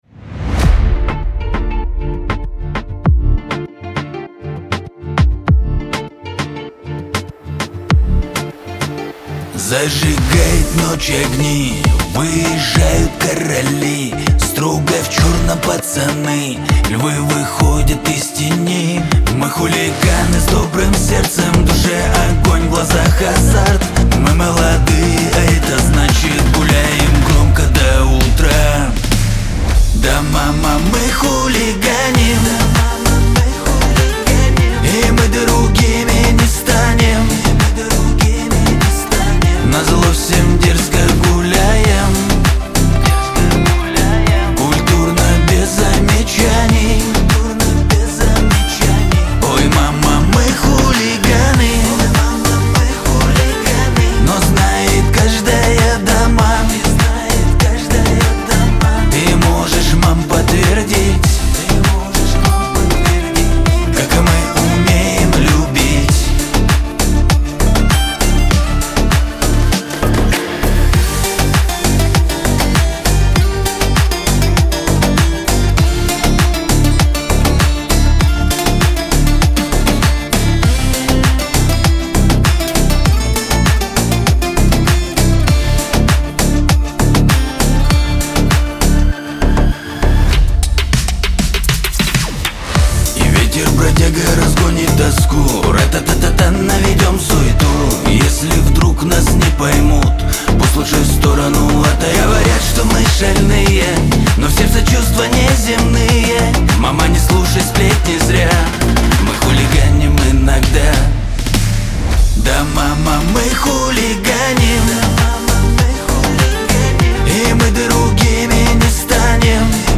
Главная ➣ Жанры ➣ Шансон. 2025.